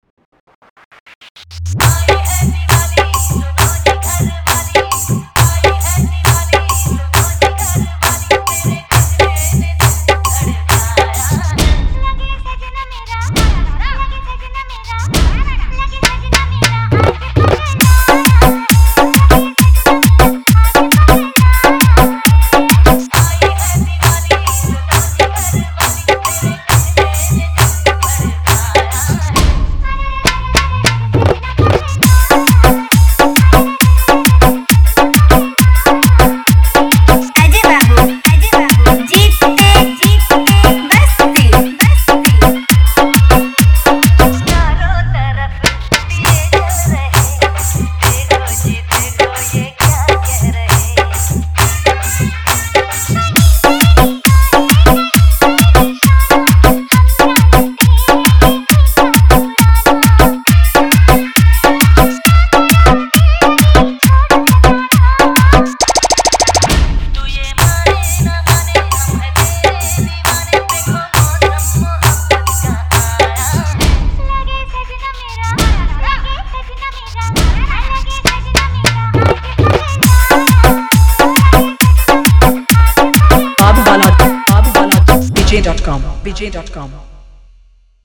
Category : Hindi Wala Dj Remix